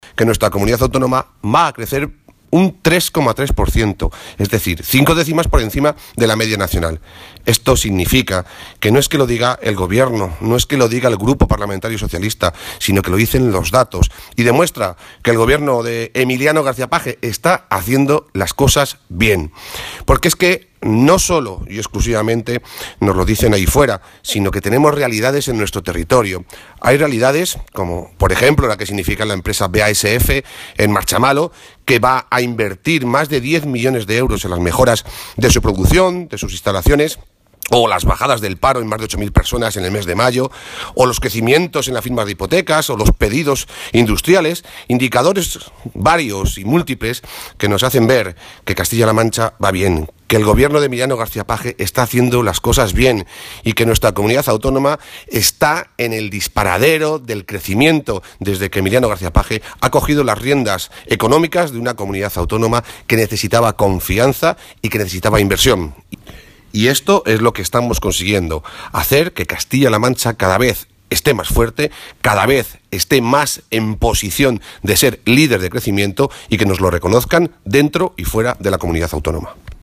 Toledo, 1 de julio de 2017.- El presidente del Grupo socialista, Rafael Esteban, ha señalado hoy que los últimos indicadores positivos que se están conociendo sobre la evolución de la economía de Castilla-La Mancha vienen a poner de manifiesto que el gobierno de Emiliano García-Page “está haciendo las cosas bien”.
Cortes de audio de la rueda de prensa